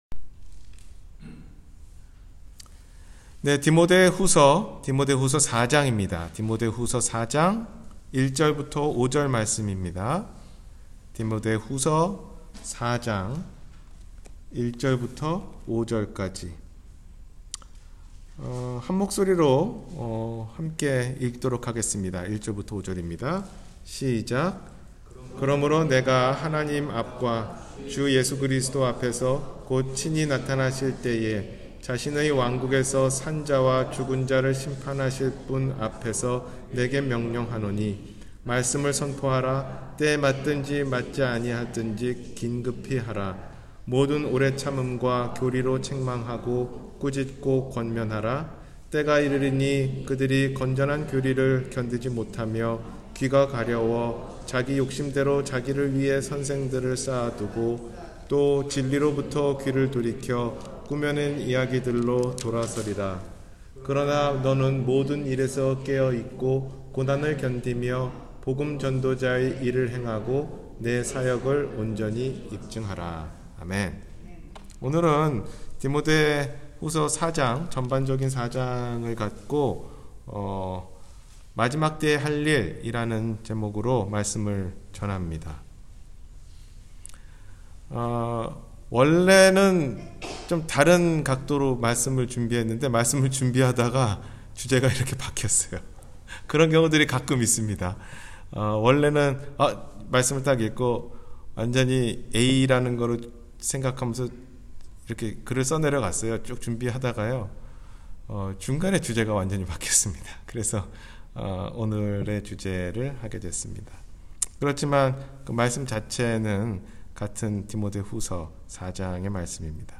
마지막 때에 할 일 – 주일설교